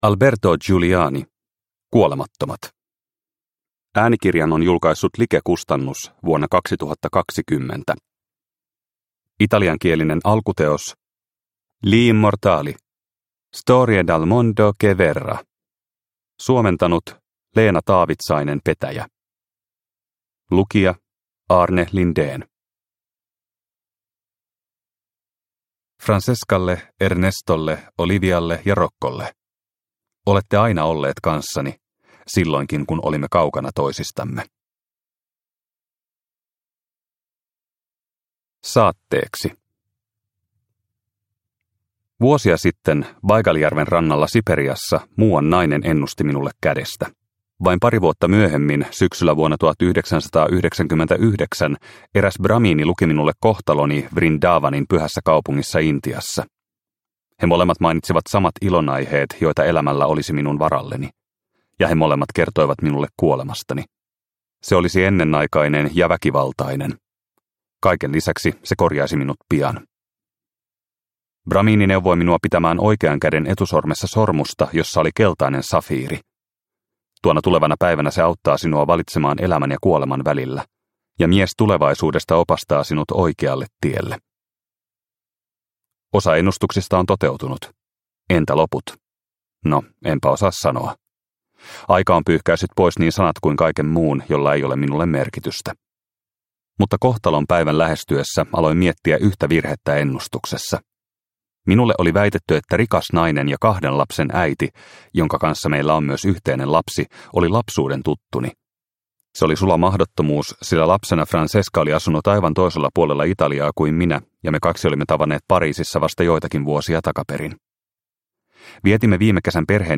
Kuolemattomat – Ljudbok – Laddas ner